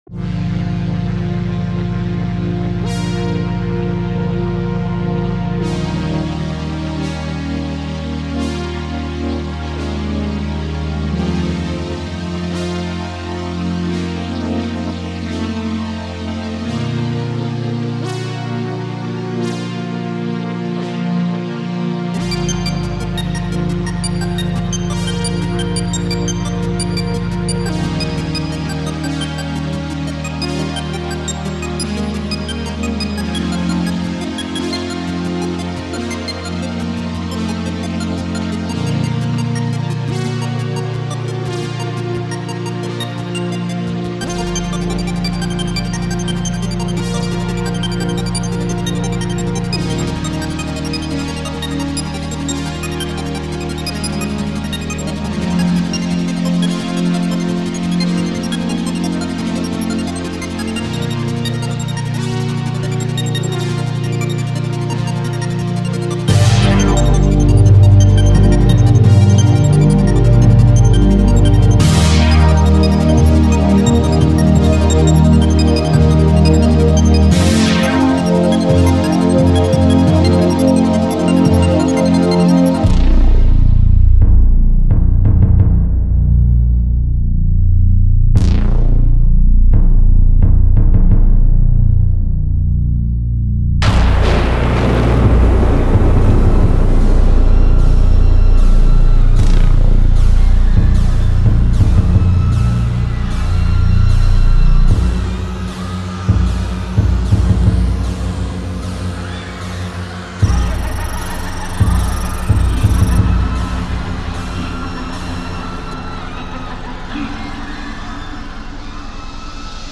synthpop jam